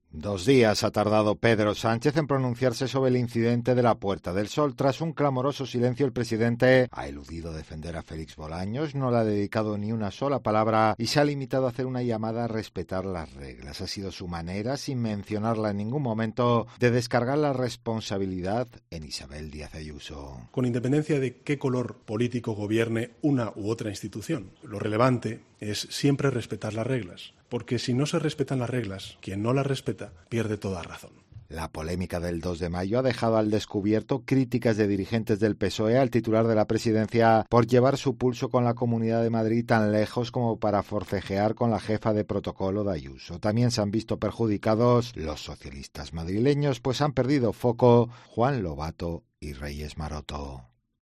En rueda de prensa tras reunirse con el presidente de Colombia, Gustavo Petro, y tras ser preguntado por si la actuación de Bolaños contó con su consentimiento, el presidente ha insistido en que en una democracia no se pueden olvidar unas mínimas reglas para la convivencia.